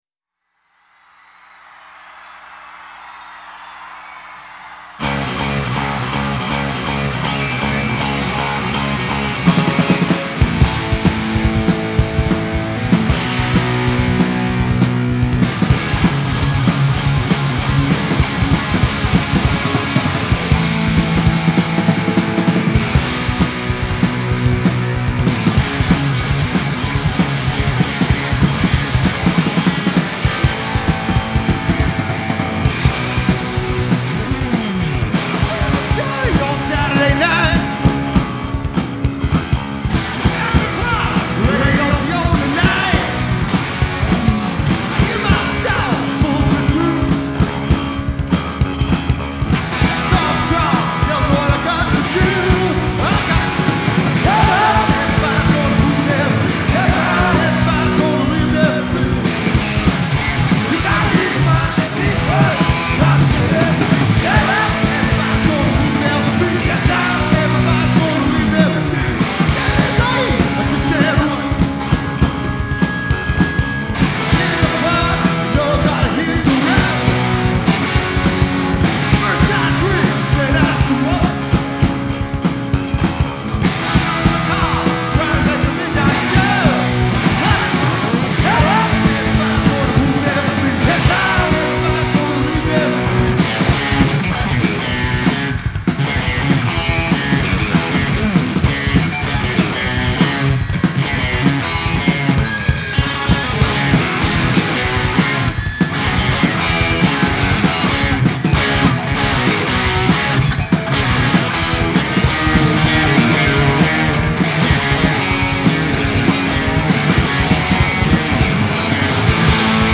Genre : Rock
Live From Mid-Hudson Civic Arena, Poughkeepsie NY